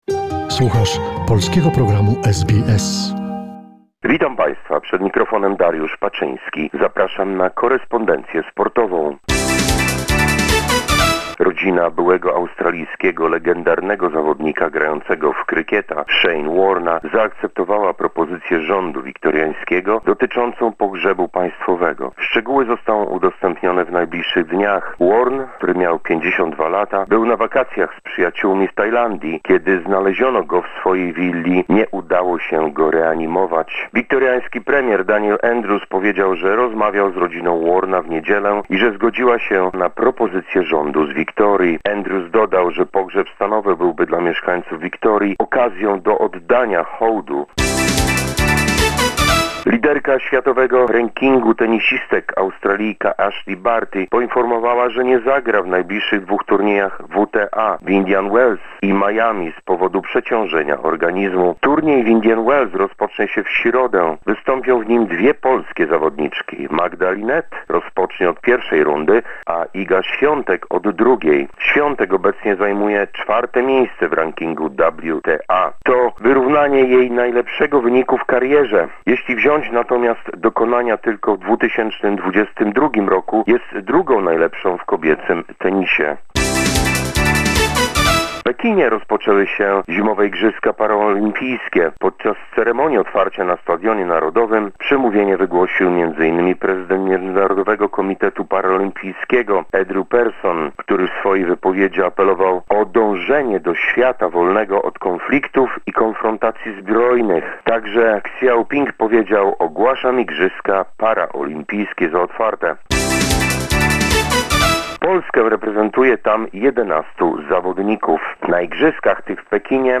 presents Monday sports summary of the week.